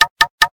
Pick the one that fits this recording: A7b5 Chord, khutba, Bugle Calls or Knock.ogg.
Knock.ogg